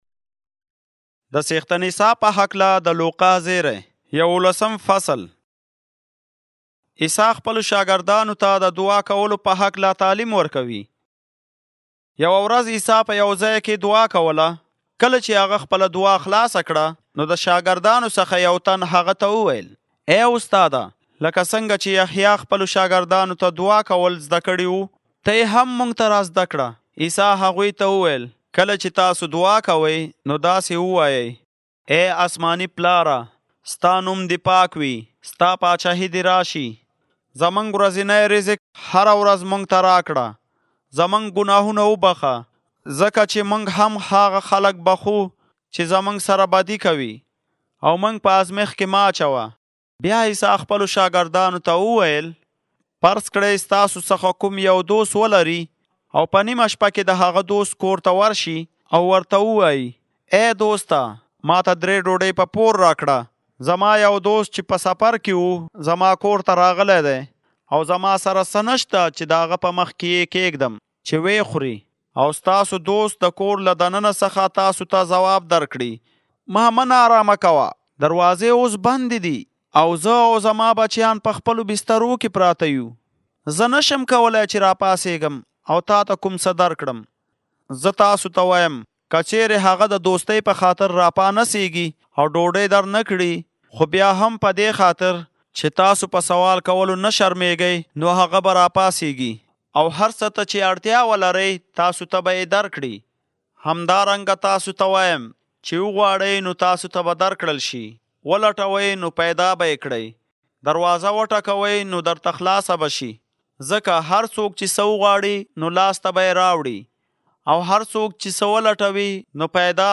Luke Audiobook (EA) – Pashto Zeray